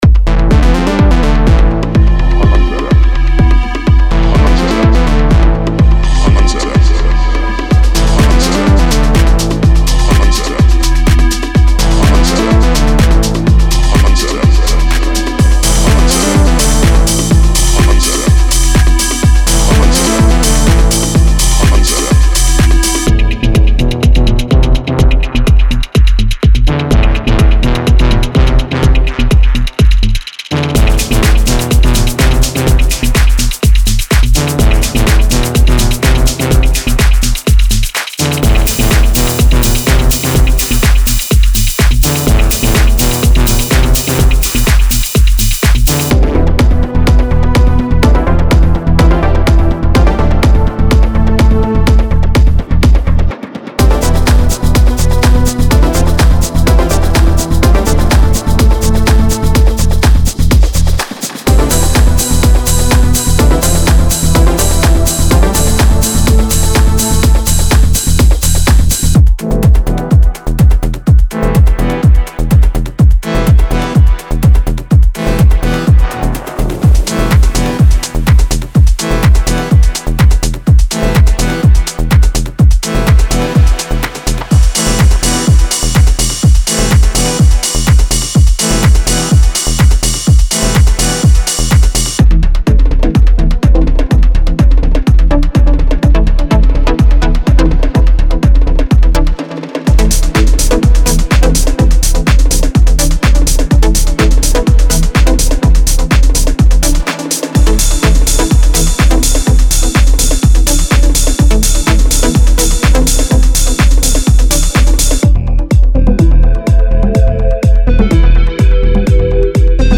Type: Midi Samples
Melodic Techno Techno
All Loops @ 125BPM.